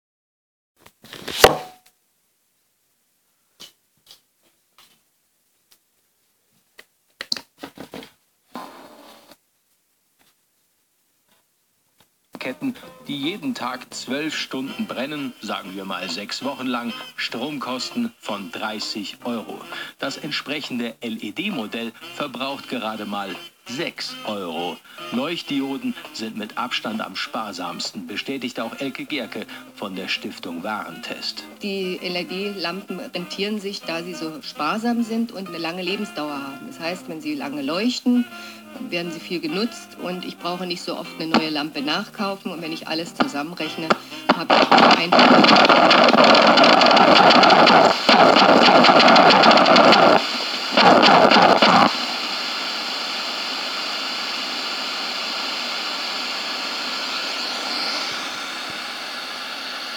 Fehlerbeschreibung: Nach dem Einschalten läuft das Radio erstmal vollkommen normal. Nach etwa 40 Sekunden treten zunehmen Empfangsausfälle auf, 10 Seknden später ist der Empfang komplett weg, kein Sender mehr auffindbar.